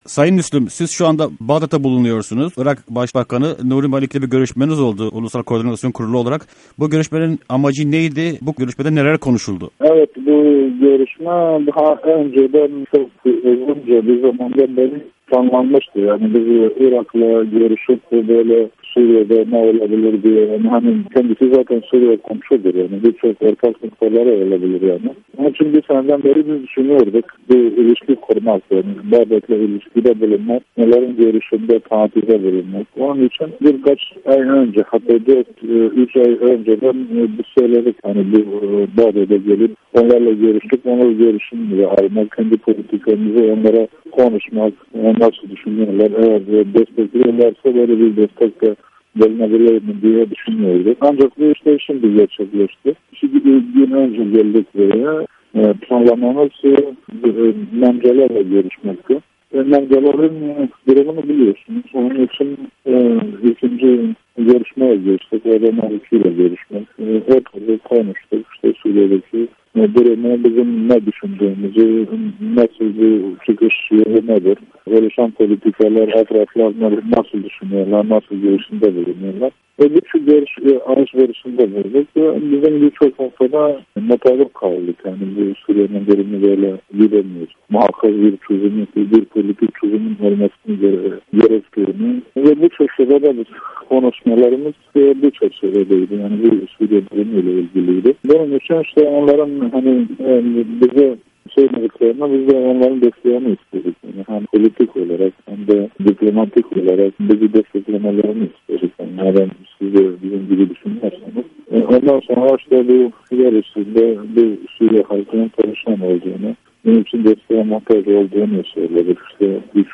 PYD Lideri Salih Müslim'le söyleşi